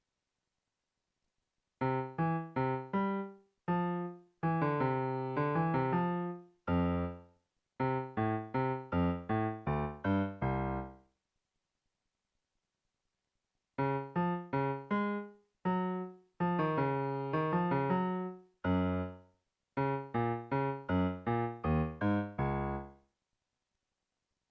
Prætoriansk stemning hedder også middeltonestemning eller quarter-camma meantone og er en typisk renaissancestemning.
temaet først i C og så i Cis